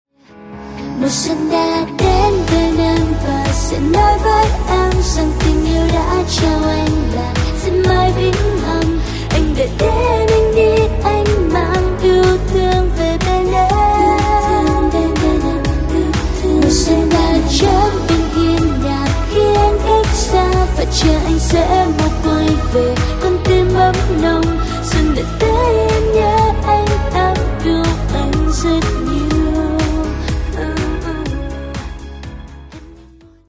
Bolero/ Trữ tình